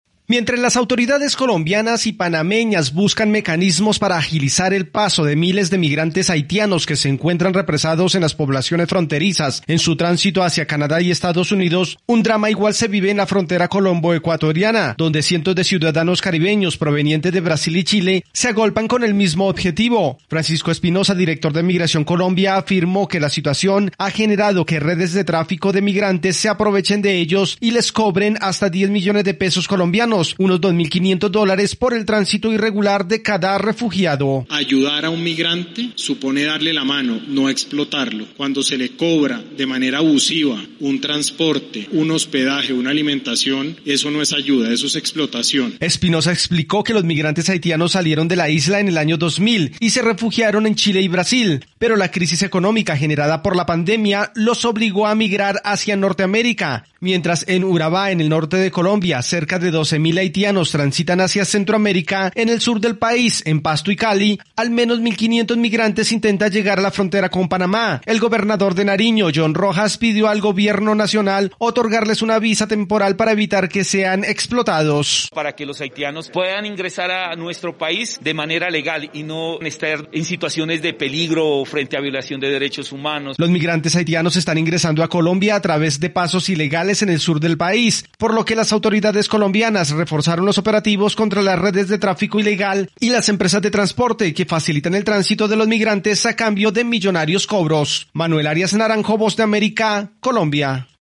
Los presidentes de Colombia, Iván Duque, y de Estados Unidos, Joe Biden, mantuvieron su primera conversación telefónica abordando importantes temas bilaterales y regionales. Desde Colombia informa el corresponsal de la Voz de América